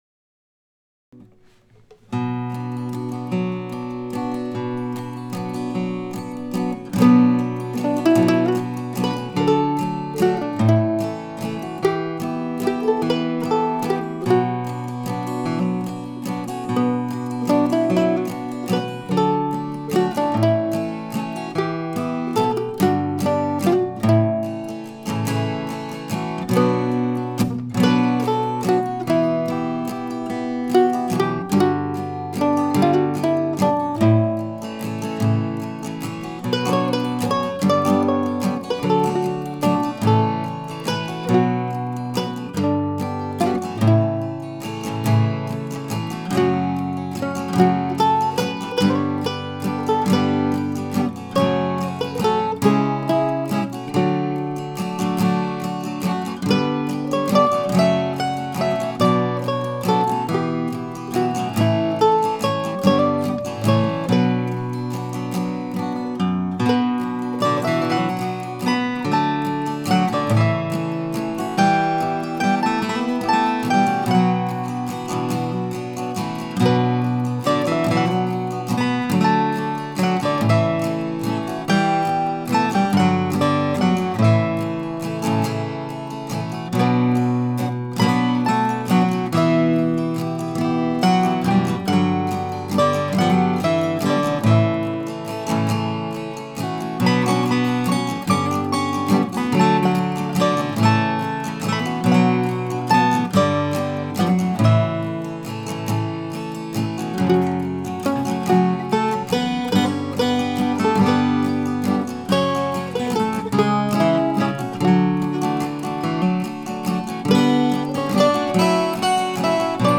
I didn't even bother with a harmony part for this one, just a simple melody with chords.